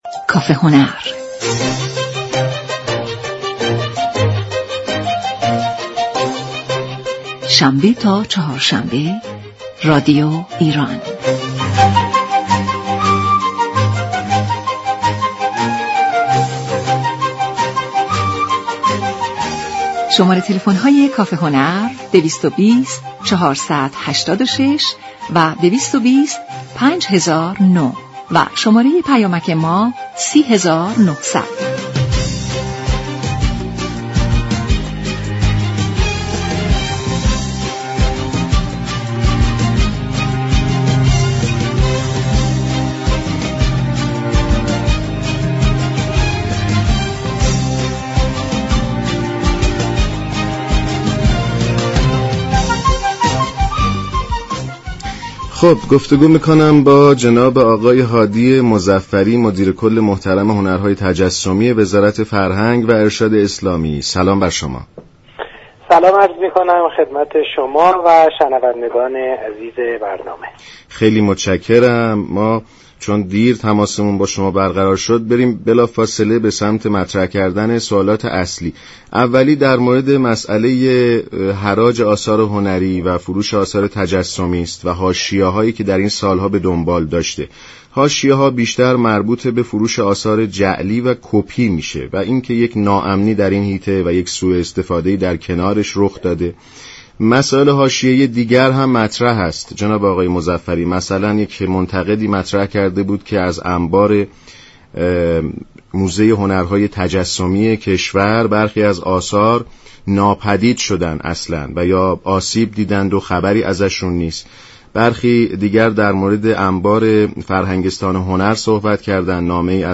به گزارش شبكه رادیویی ایران، هادی مظفری مدیر كل هنرهای تجسمی وزارت فرهنگ و ارشاد اسلامی در گفت و گو با برنامه كافه هنر درباره فروش و حراج آثار جعلی و كپی آثار هنری گفت: وزارت فرهنگ و ارشاد اسلامی پیش از فروش و حراج آثار، هیچگونه نظارتی بر نحوه قیمت گذاری ندارد.